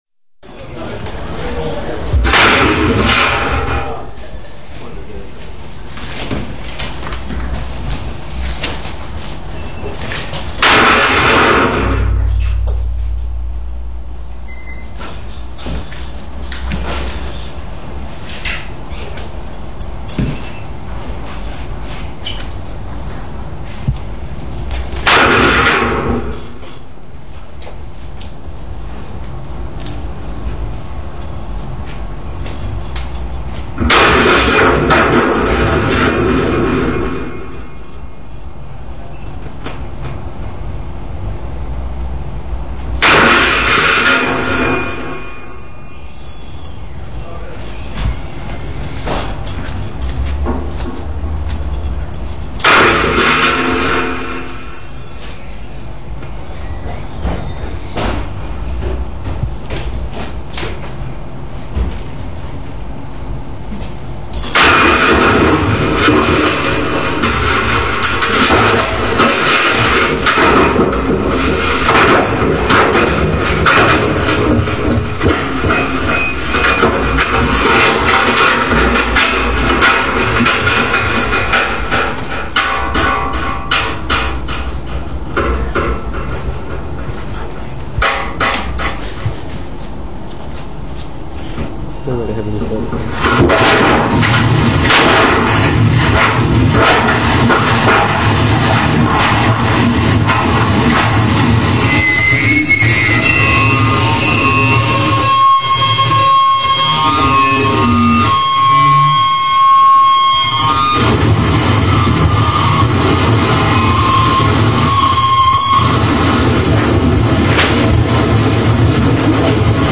live at Pony 9 February 2008